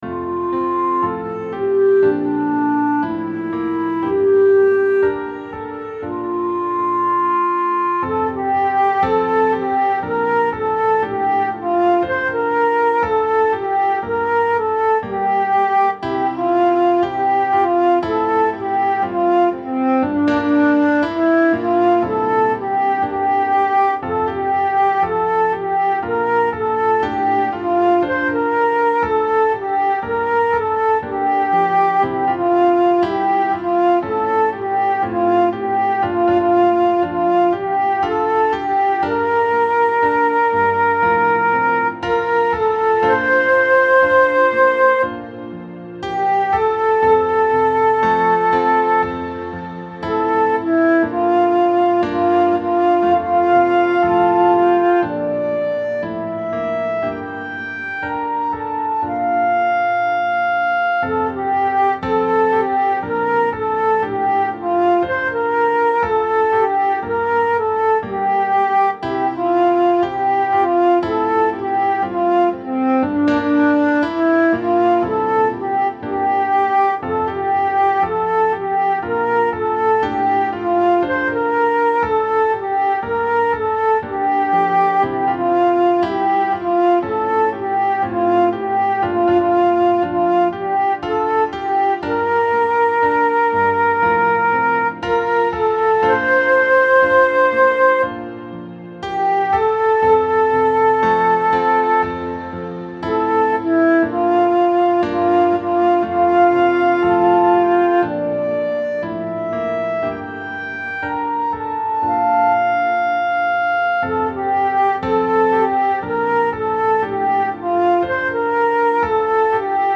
Blessed_Night_Blessed-Light_Backing.mp3